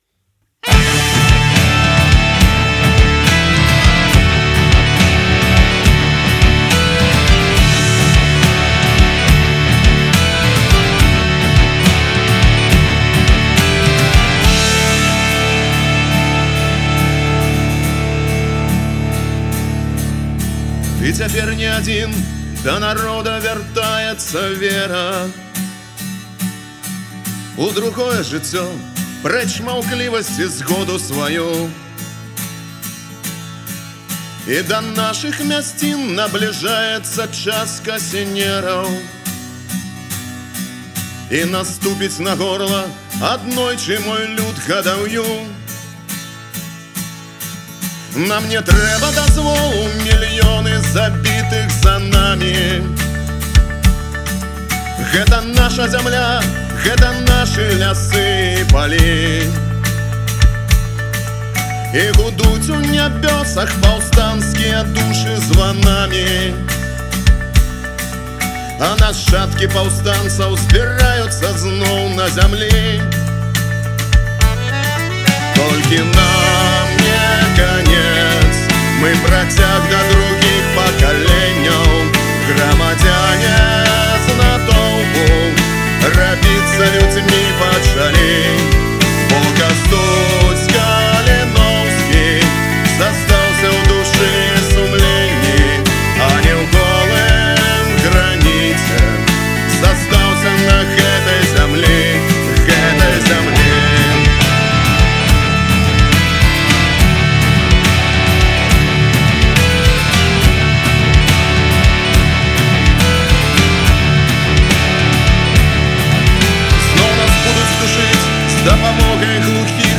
Фольк-панк гурт заснаваны ў 2012 ў Горадні.